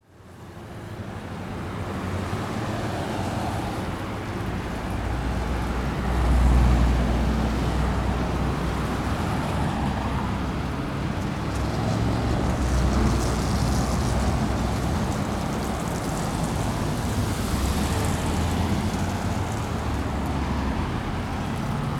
Шум городского трафика